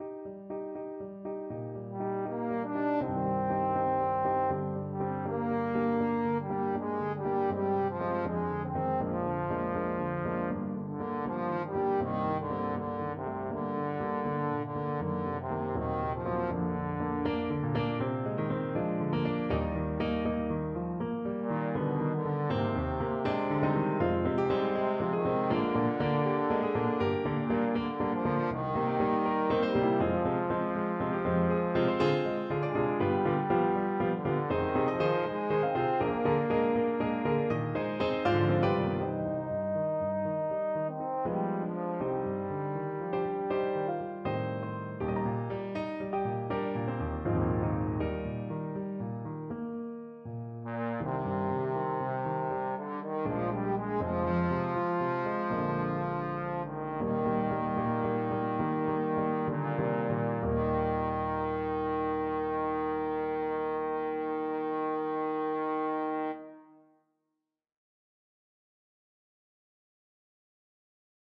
Trombone version
4/4 (View more 4/4 Music)
Ab3-F5
= 80 Moderato